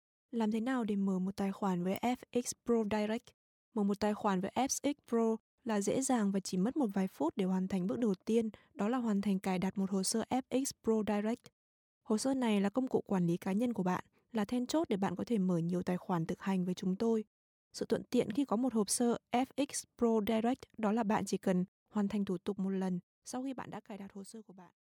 Sprechersuche Professionelle Sprecher und Sprecherinnen | All Audio GmbH
Weiblich